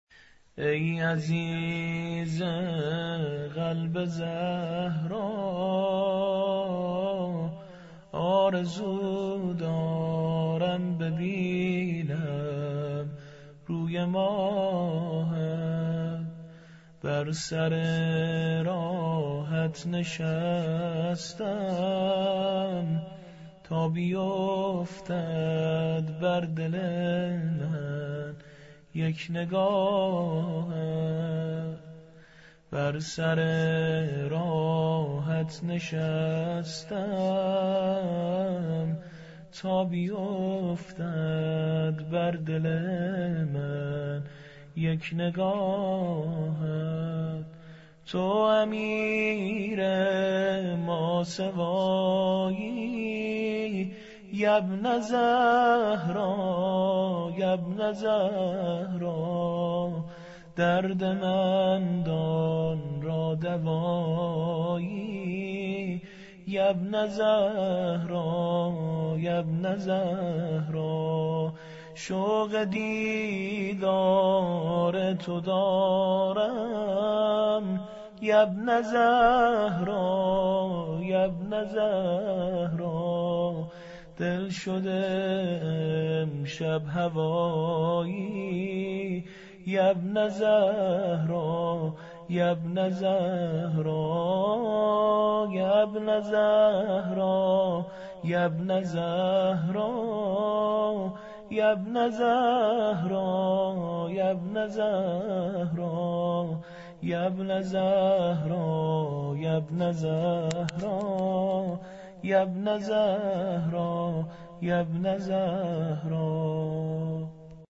زمزمه